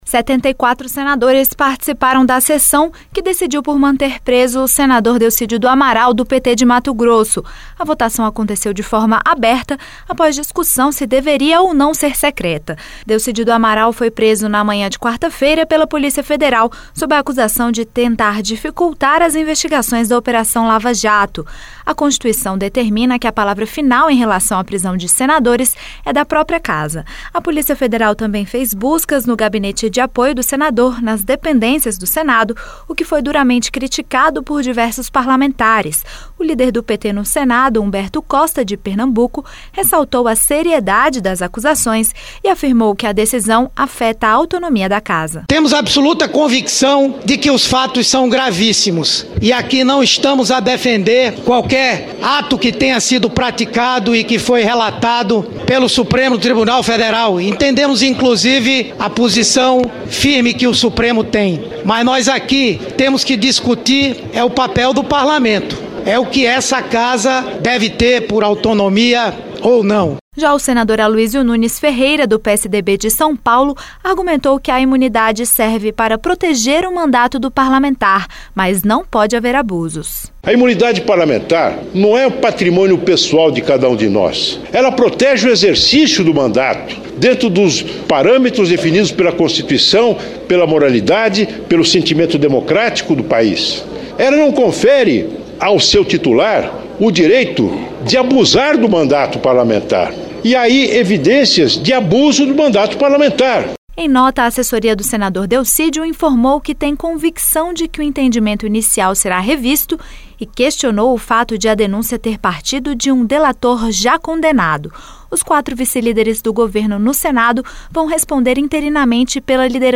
74 senadores participaram da sessão que decidiu manter preso o senador Delcídio do Amaral (PT-MS). A votação foi aberta, após discussão sobre se deveria ou não ser secreta. Ouça mais informações na reportagem